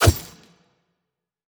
轻攻击4.wav